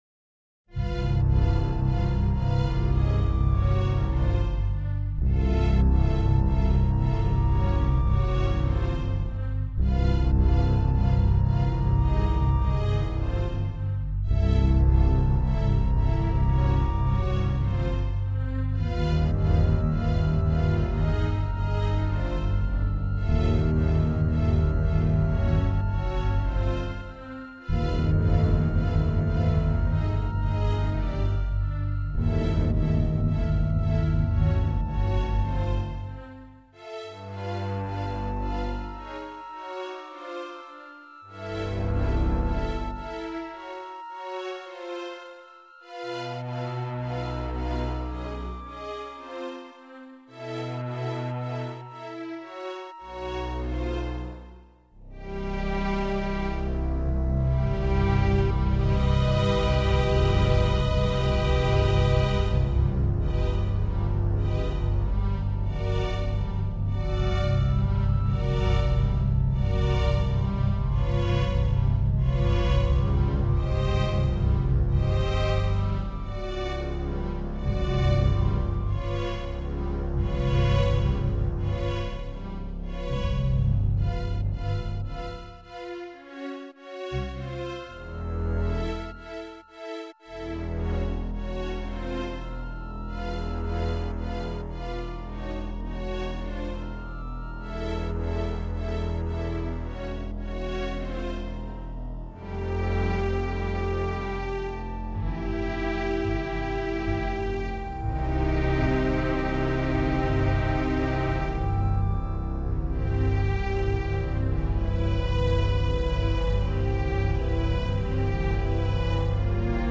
Background Music to Crockglades 2 Area for video game Rather strange tune